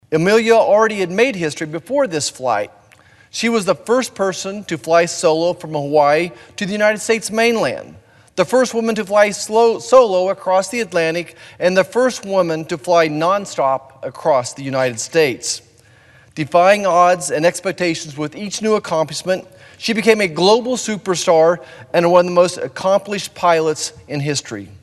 Senator Marshall noted that Earhart and her navigator went missing on a flight around the world at the Equator 85 years ago this month.